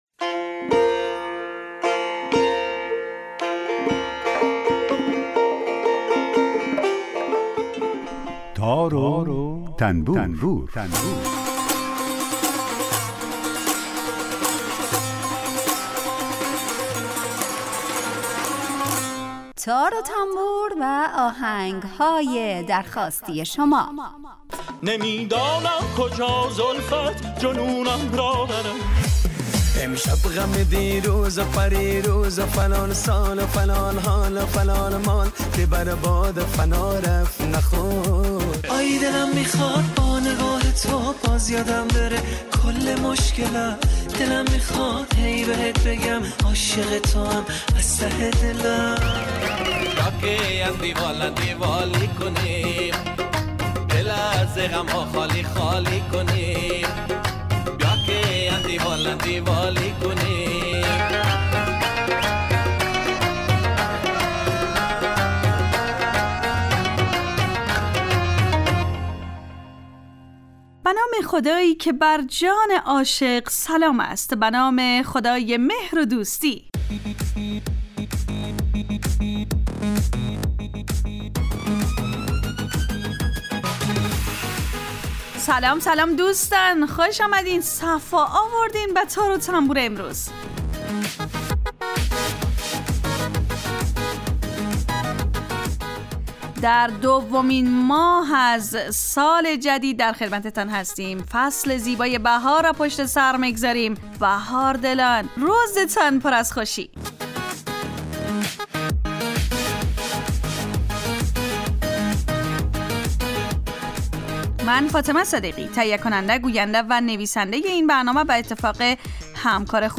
برنامه ای با آهنگ های درخواستی شنونده ها
یک قطعه بی کلام درباره همون ساز هم نشر میکنیم